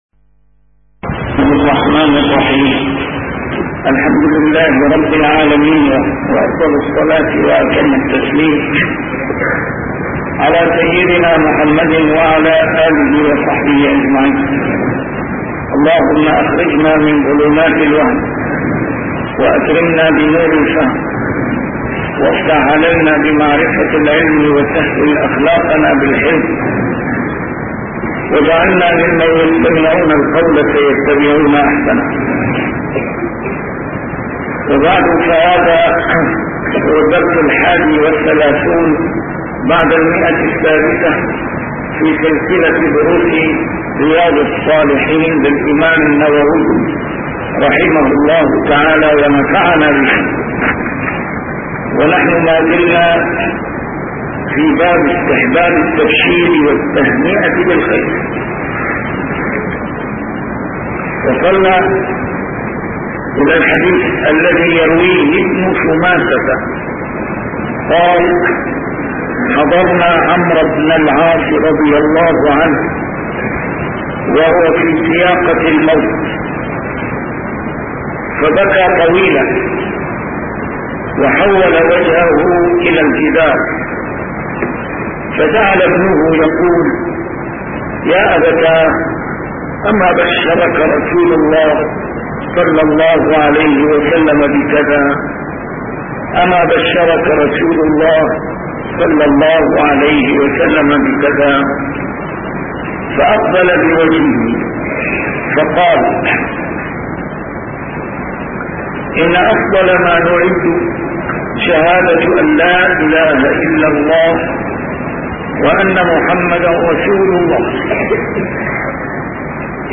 شرح كتاب رياض الصالحين - A MARTYR SCHOLAR: IMAM MUHAMMAD SAEED RAMADAN AL-BOUTI - الدروس العلمية - علوم الحديث الشريف - 631- شرح رياض الصالحين: استحباب التبشير والتهنئة بالخير